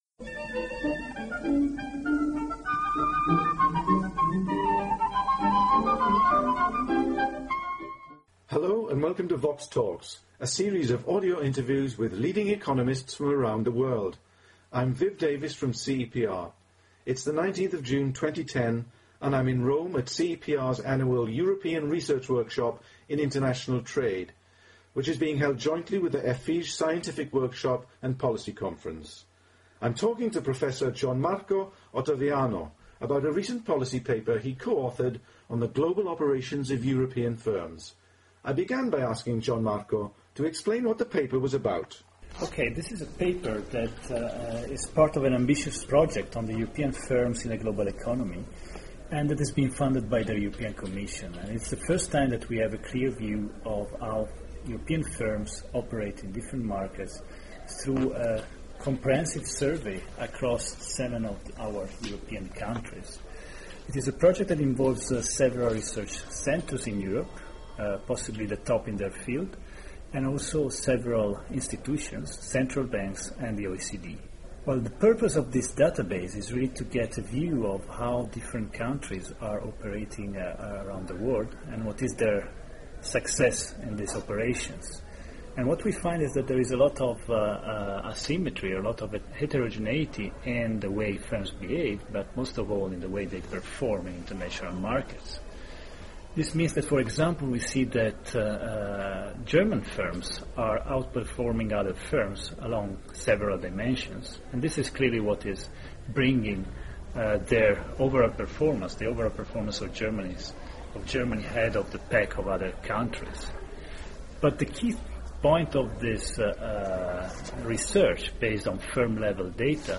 He argues that firms can improve their competitiveness within the European single market, but competing effectively in the future will require more than just exporting to neighbouring EU countries. The interview was recorded in Rome on 19 June 2010.